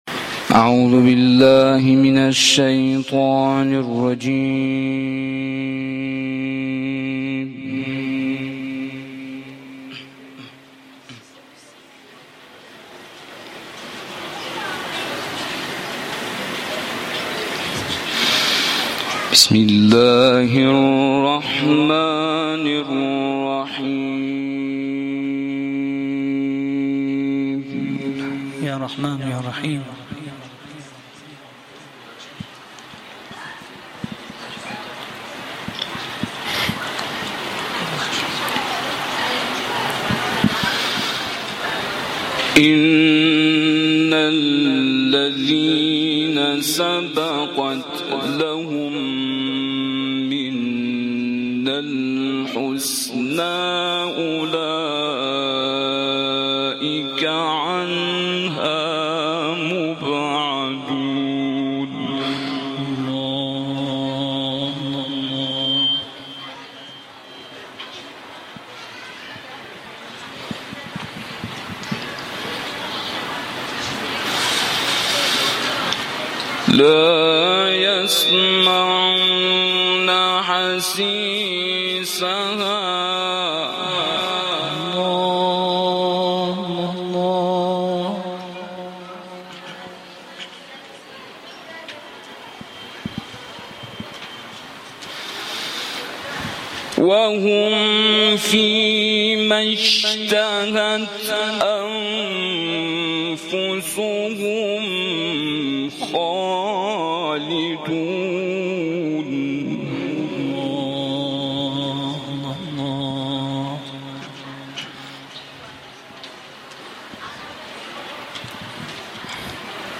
کرسی تلاوت و تفسیر قرآن کریم شهرستان علی آباد کتول